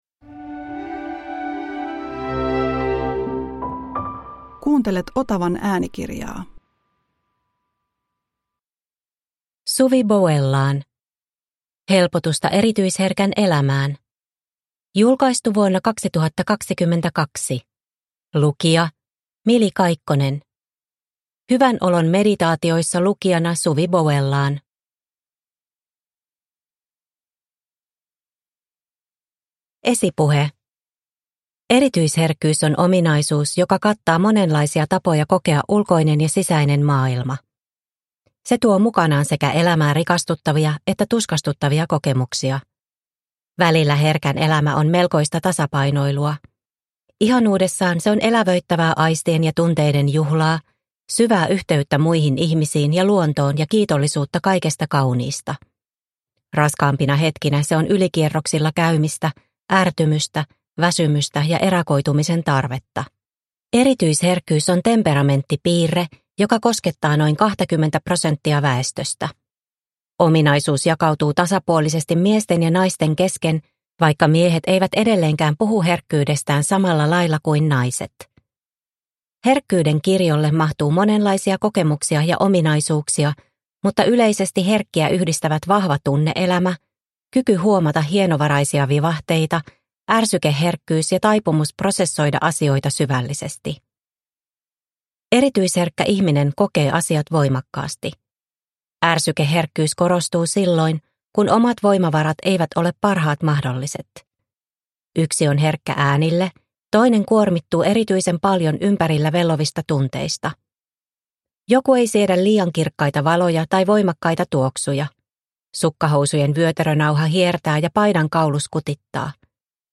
Helpotusta erityisherkän elämään – Ljudbok – Laddas ner